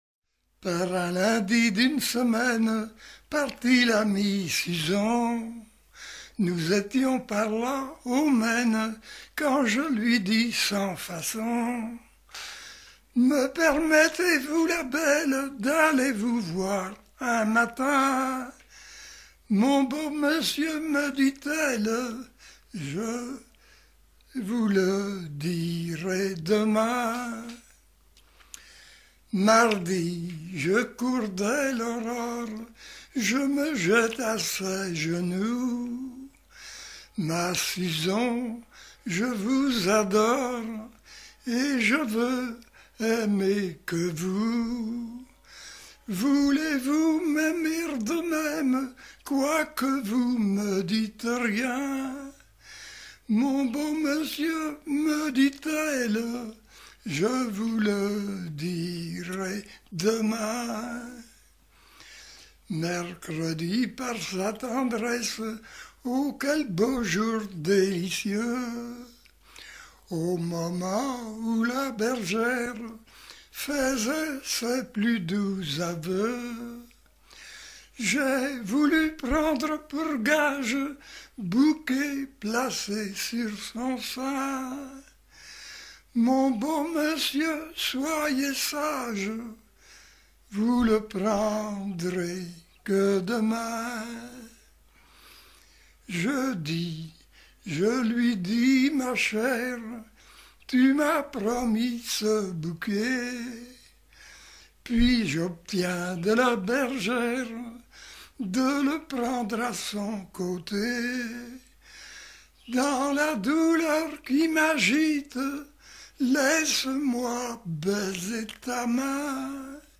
circonstance : bachique
Genre énumérative